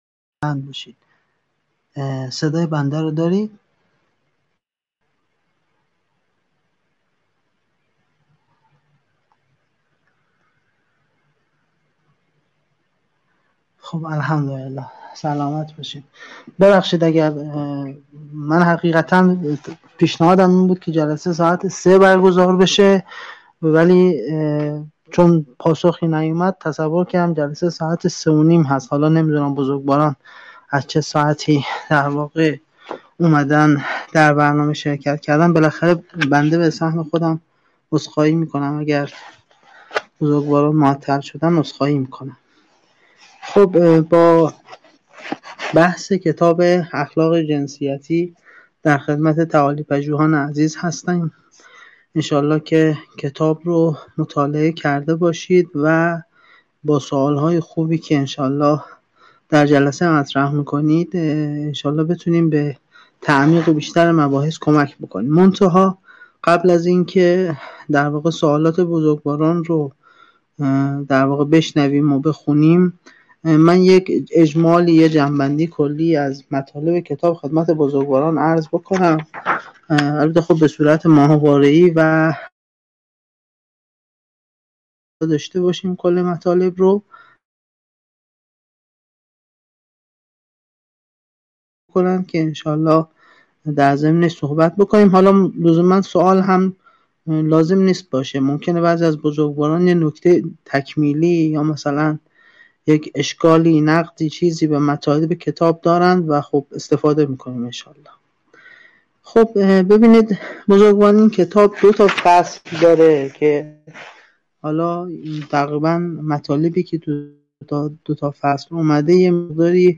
حقوق زن و مرد از نگاه اسلام - اخلاق جنسیتی (بینش مطهر) - جلسه-پرسش-و-پاسخ